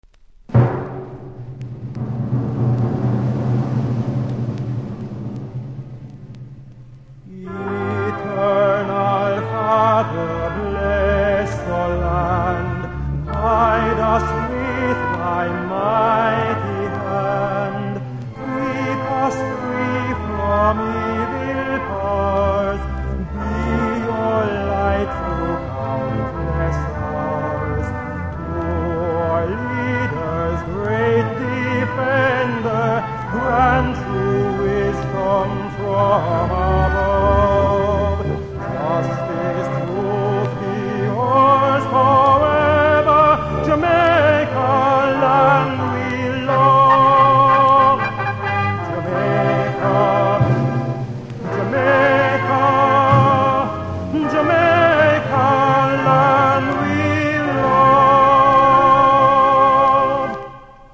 きずはそこそこありますがノイズは少ないので試聴で確認下さい。